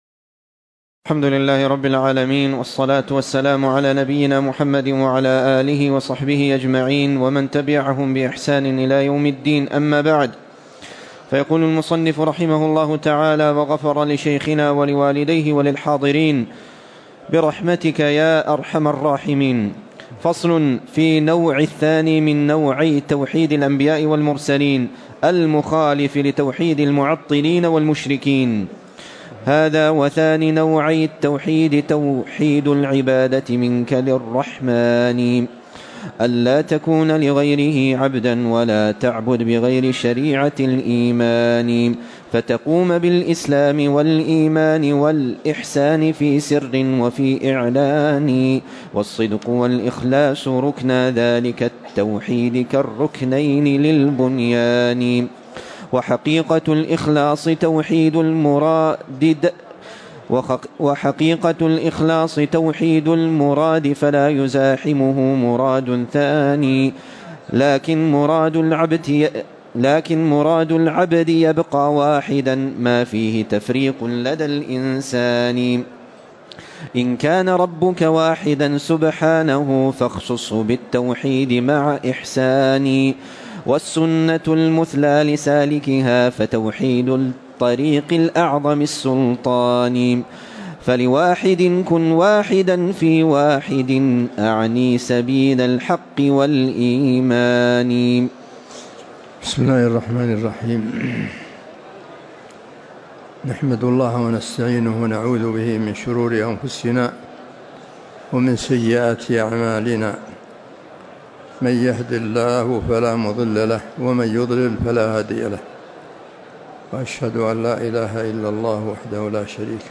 تاريخ النشر ١١ جمادى الأولى ١٤٤١ هـ المكان: المسجد النبوي الشيخ